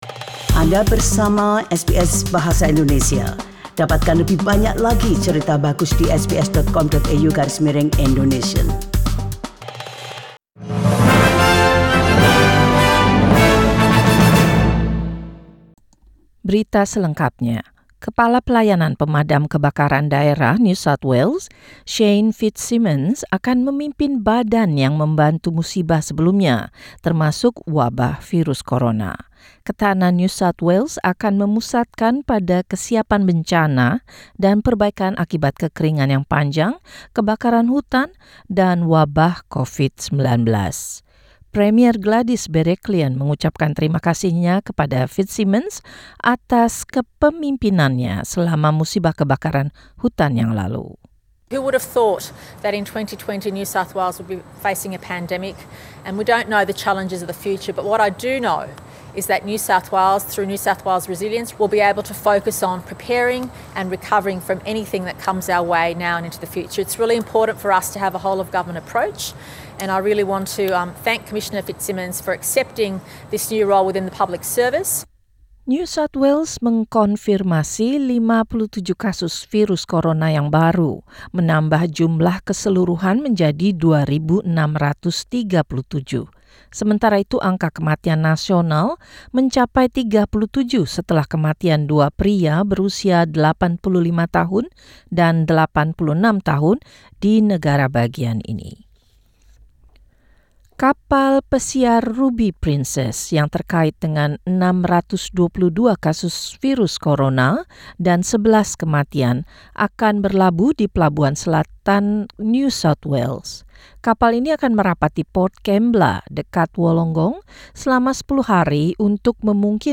SBS Radio News in Indonesian - 6 April 2020